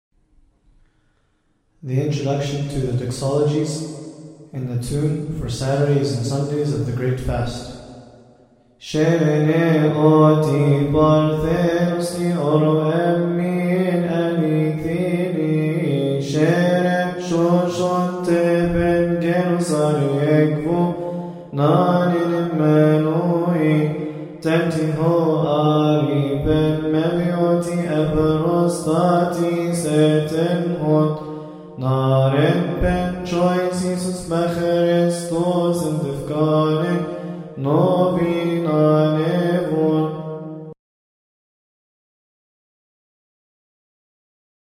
All hymns must be chanted according to the Higher Institute of Coptic Studies.
Sundays of the Great Fast) (C)